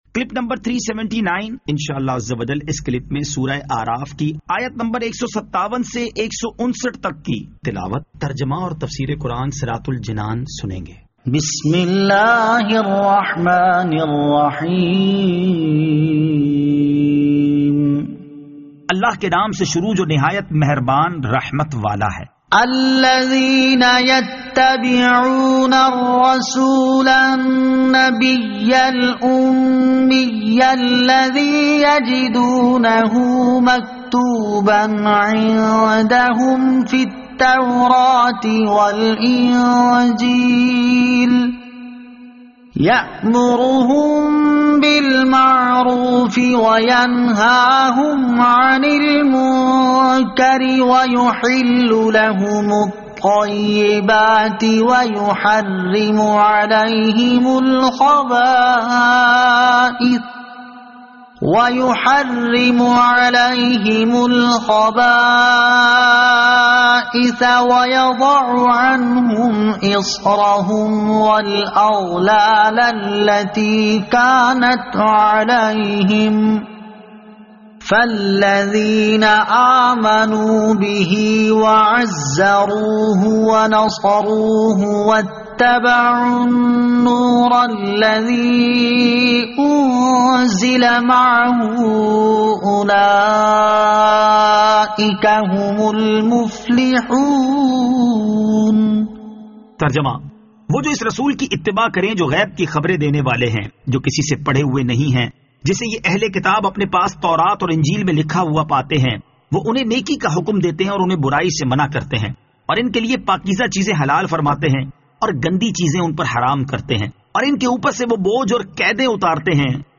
Surah Al-A'raf Ayat 157 To 159 Tilawat , Tarjama , Tafseer